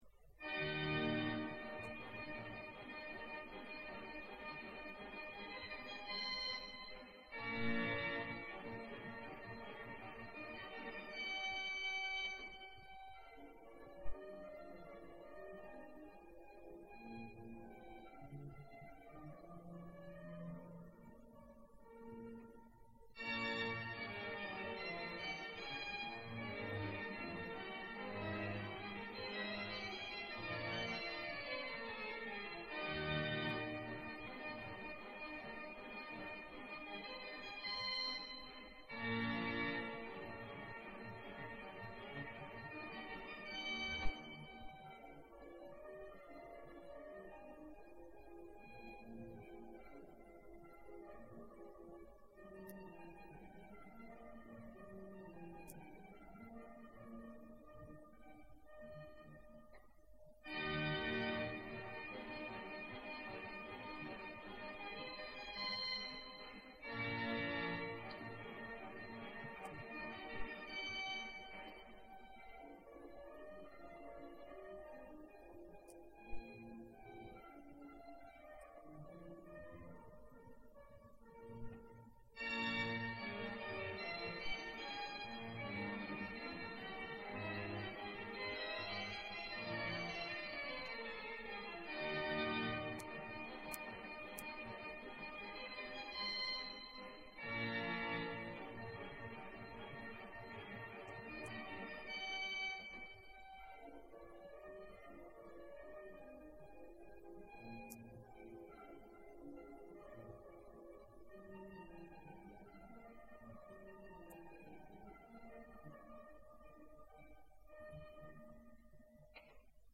Los primeros conciertos de órgano
Algunos fragmentos audio del concierto de ICADE, pero de una calidad regular, según los medios técnicos de aquella época.